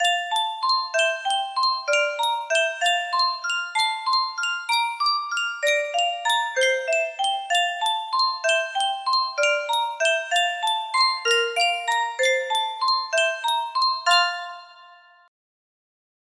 Sankyo Music Box - Tchaikovsky Waltz of the Snowflakes EiL music box melody
Full range 60